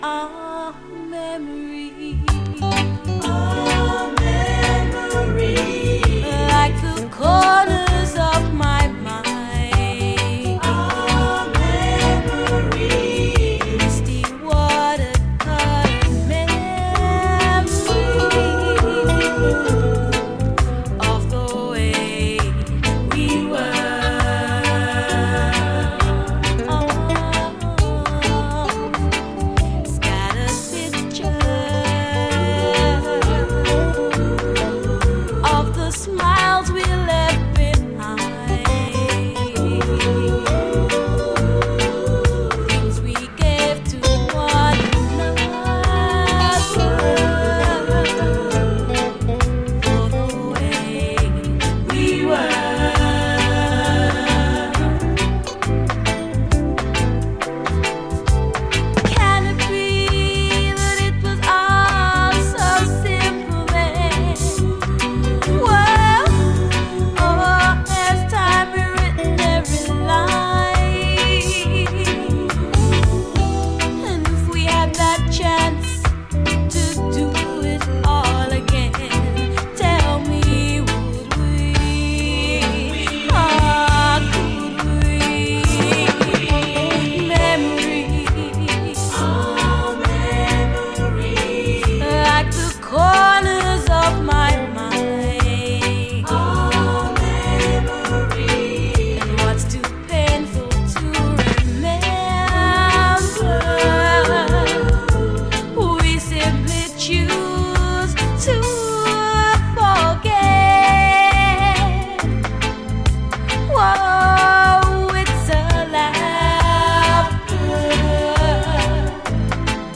ブラス・フレーズ一発でアガるキラー・ハートフル・レゲエ
男女デュエットでの